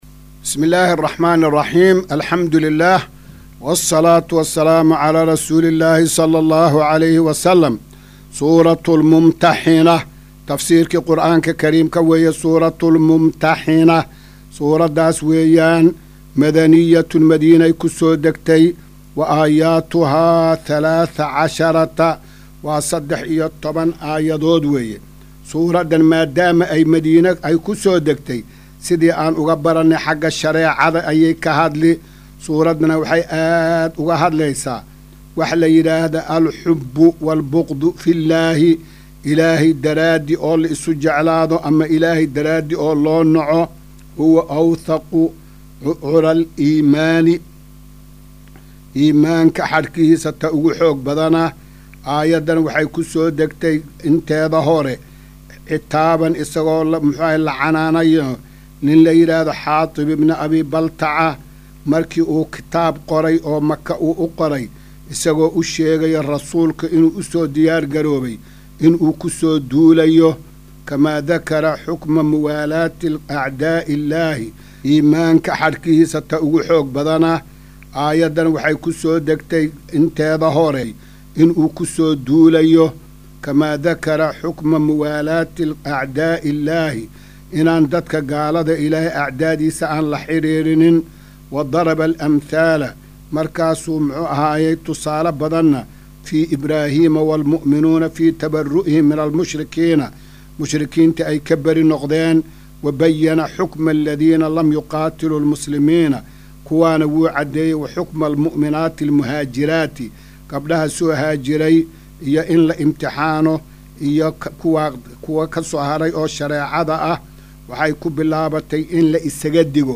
Maqal:- Casharka Tafsiirka Qur’aanka Idaacadda Himilo “Darsiga 262aad”